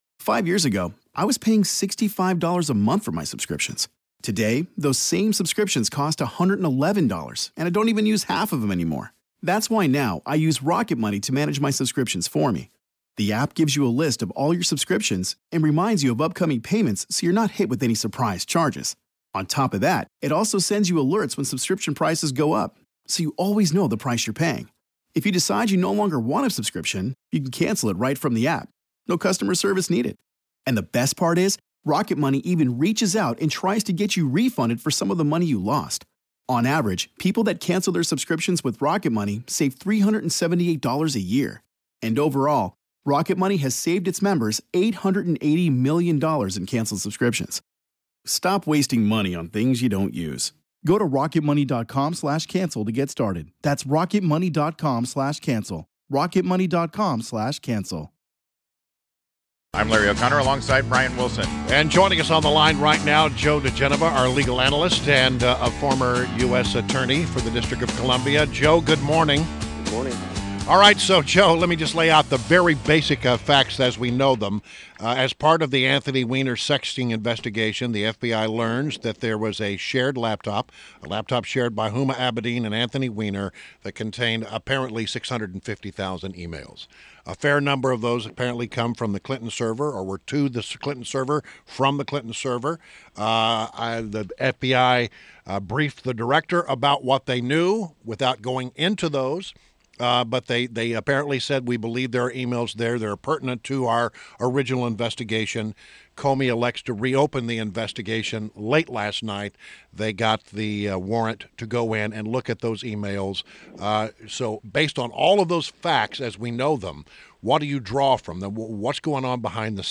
WMAL Interview - JOE DIGENOVA - 10.31.16
INTERVIEW – JOE DIGENOVA – legal analyst and former U.S. Attorney to the District of Columbia